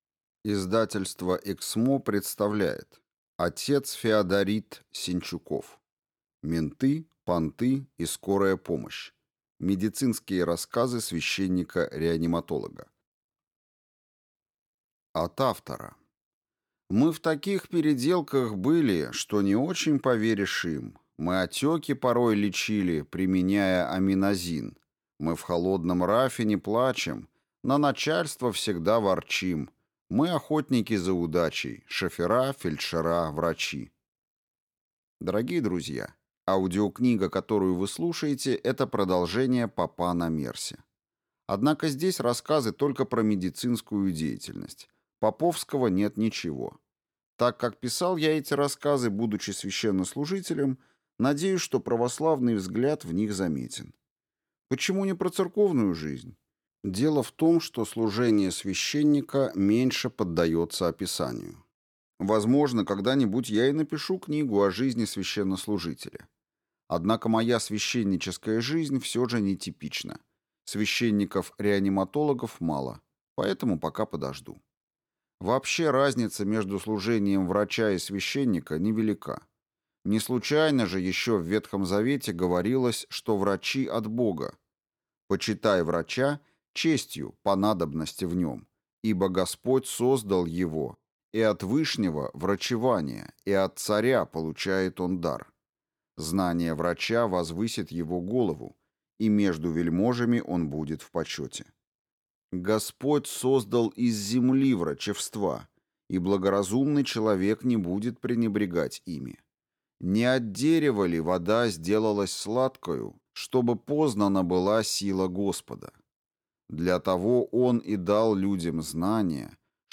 Аудиокнига Менты, понты и «Скорая помощь». Медицинские рассказы священника-реаниматолога | Библиотека аудиокниг